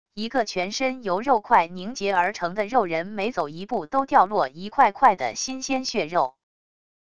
一个全身由肉块凝结而成的肉人每走一步都掉落一块块的新鲜血肉wav音频